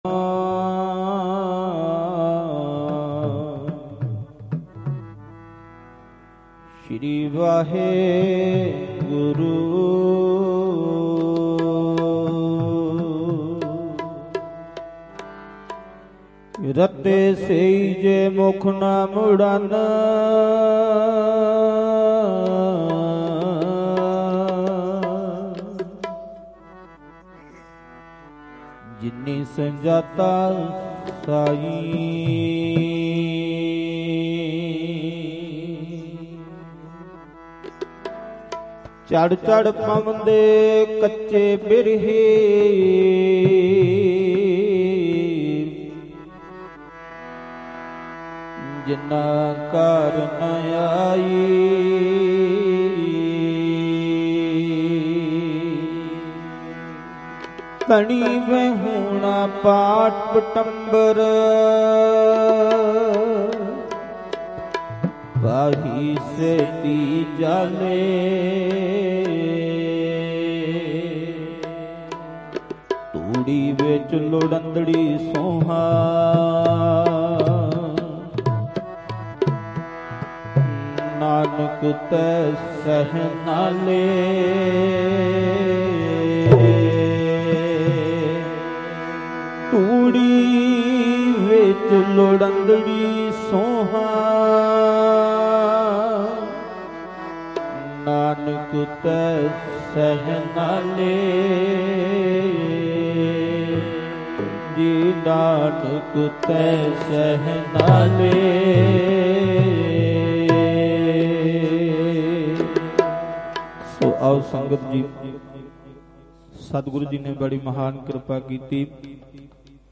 Live gurmat samagam Bhilai Chhattisgarh 17 jan 2026